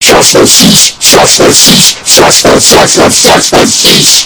Shush The Sheesh Earrape